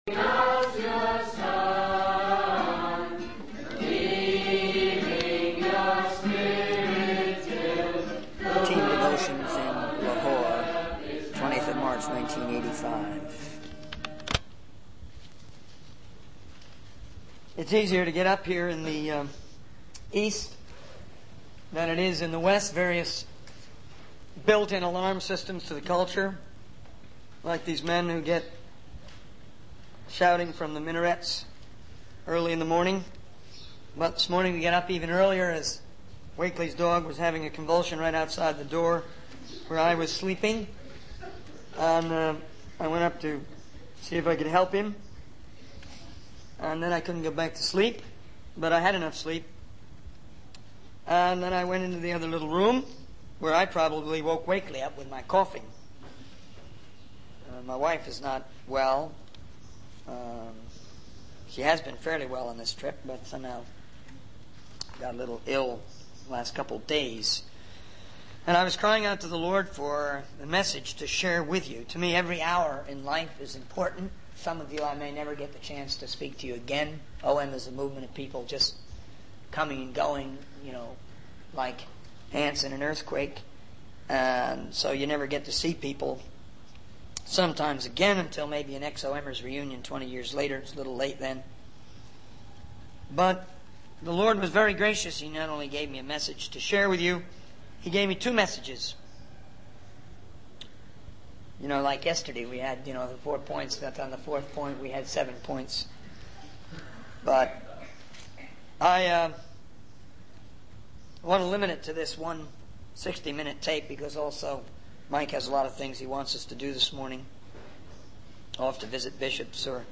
In this sermon, the speaker shares that he has been given two messages to share with the audience. The first message is about seven major obstacles to the work in India, specifically geared towards the Indian national situation.